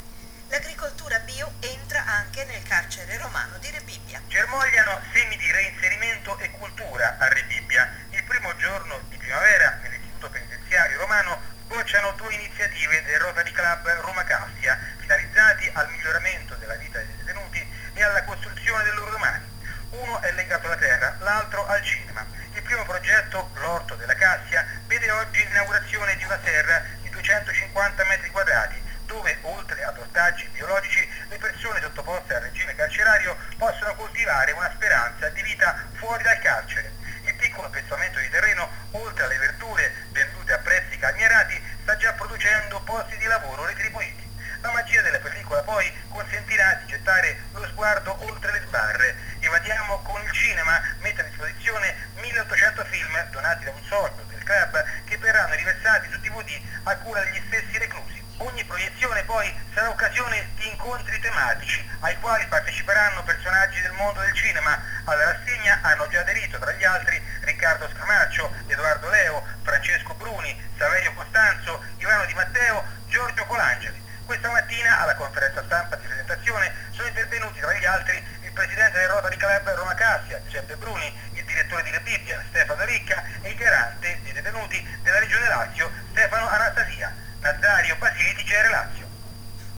e qui sotto il link per il servizio andato in onda alla radio: